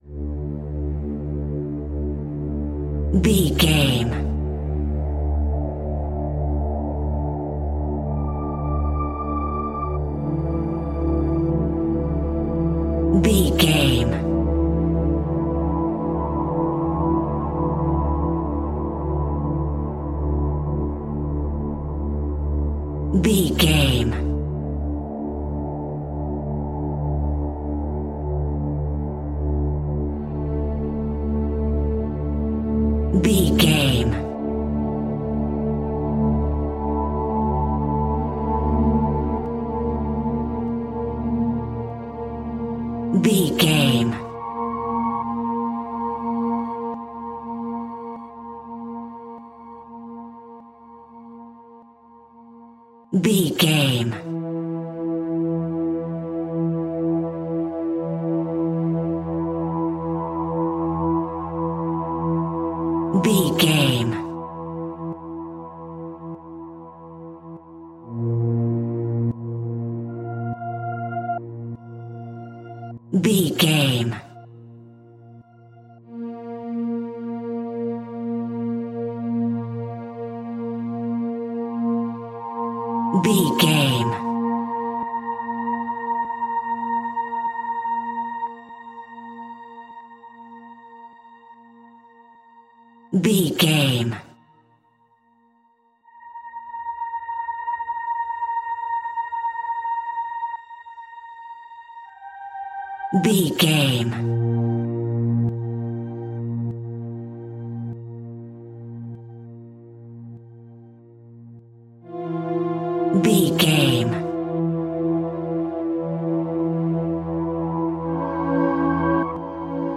Aeolian/Minor
D
ominous
dark
suspense
haunting
eerie
synthesizer
ambience
pads
eletronic